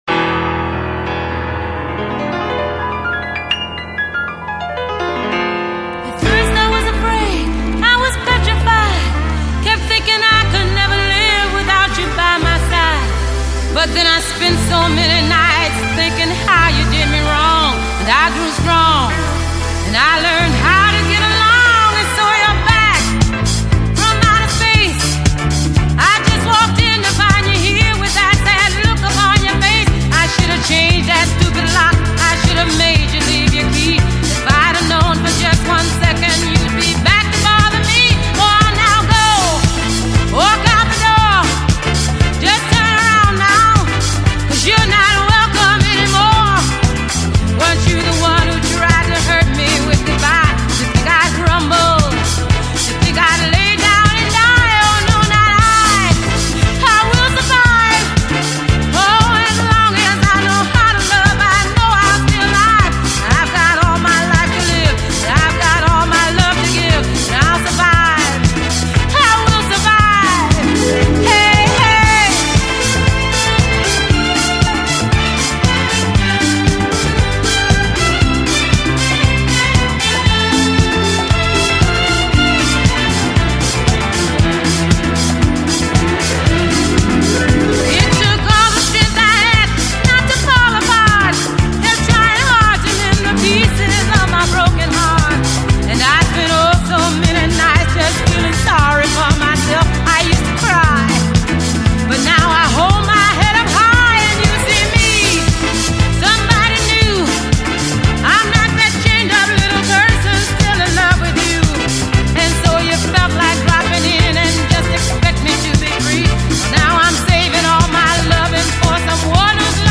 американская певица в стиле диско